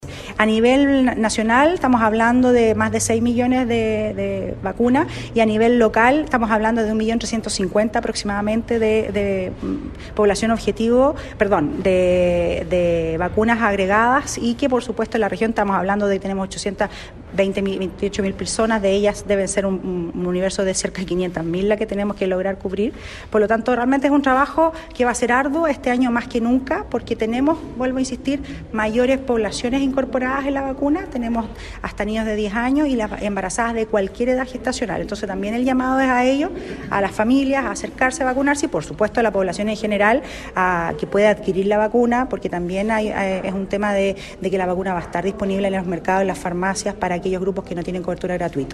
Scarleth Molt fue enfática en llamar a la población a vacunarse desde este lunes 16 de marzo en la campaña influenza 2020, ya que este año se hace más necesario que nunca.